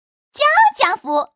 Index of /poker_paodekuai/update/1526/res/sfx/changsha_woman/